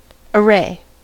array: Wikimedia Commons US English Pronunciations
En-us-array.WAV